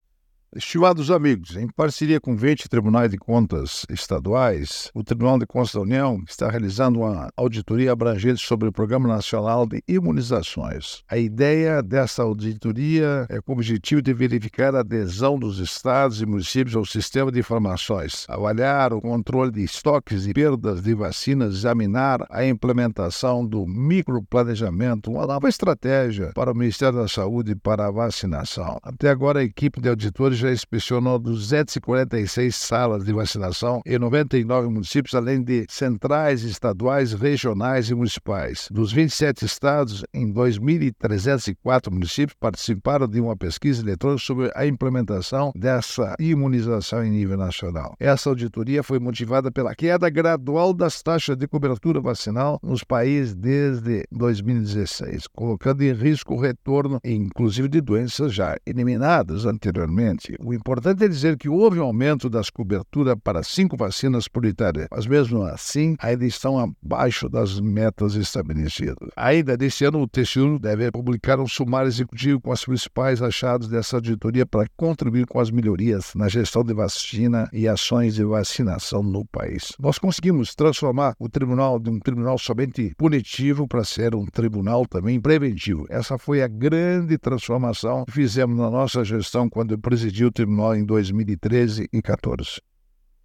É o assunto do comentário desta terça-feira (27/08/24) do ministro Augusto Nardes (TCU), especialmente para OgazeteitO.